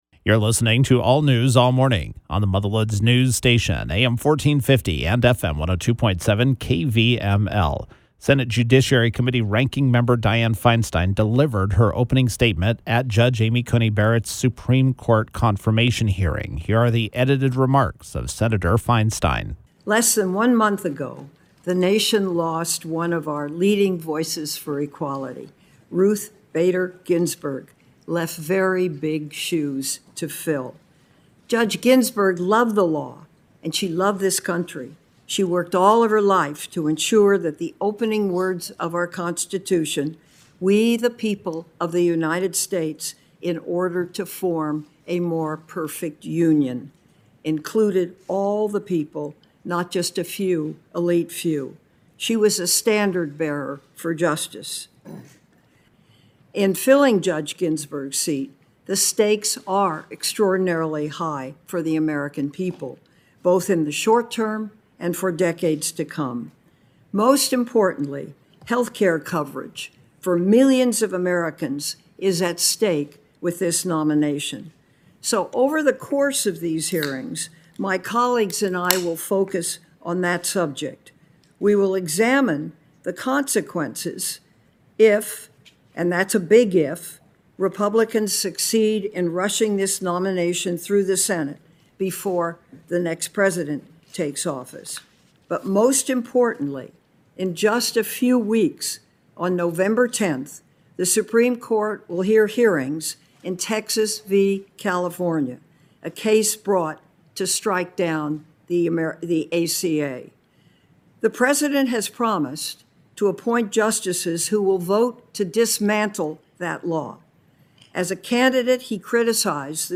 Senate Judiciary Committee Ranking Member Dianne Feinstein (D-Calif.) delivered her opening statement at Judge Amy Coney Barrett’s Supreme Court confirmation hearing.